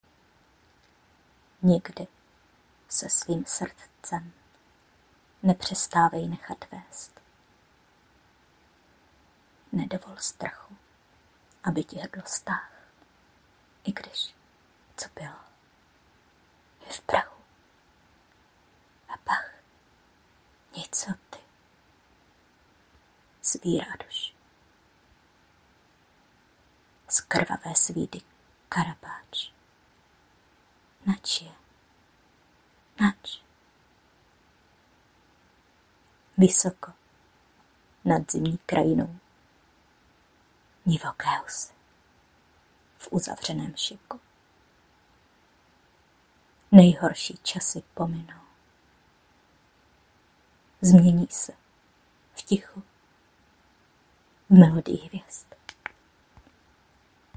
Básně » Láska